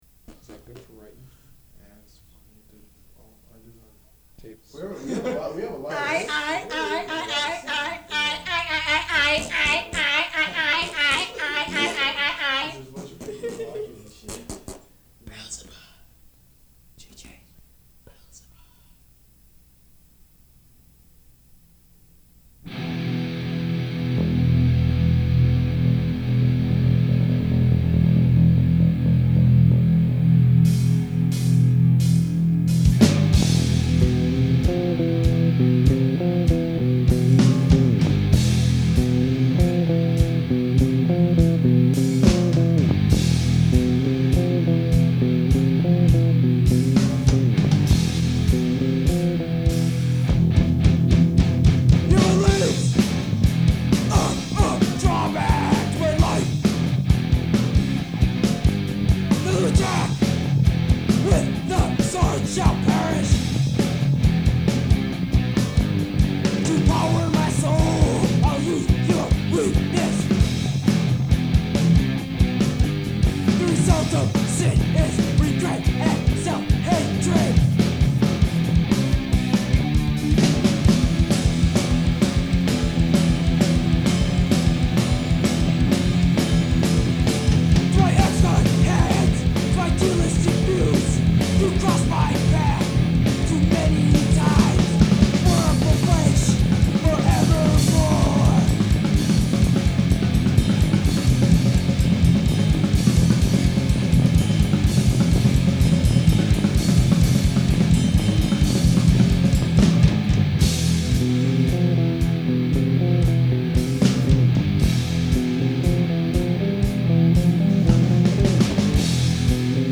Filed under: Hardcore , Straight Edge